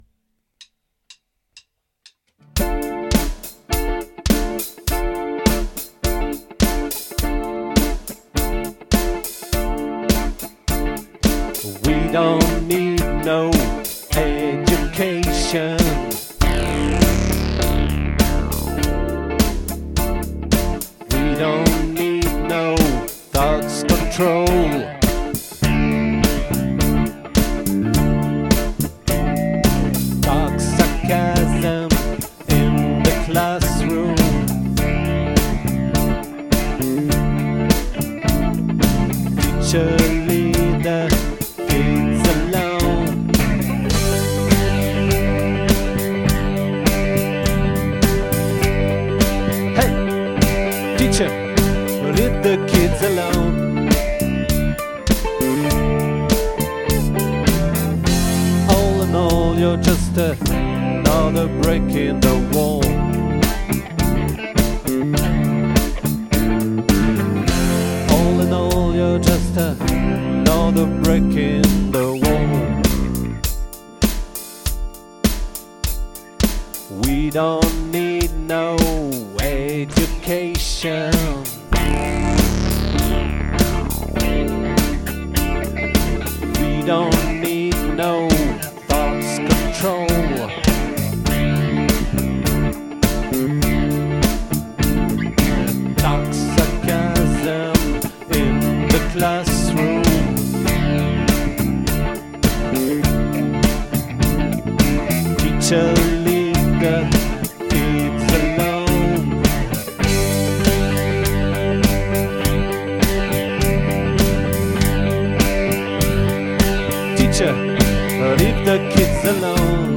🏠 Accueil Repetitions Records_2023_02_15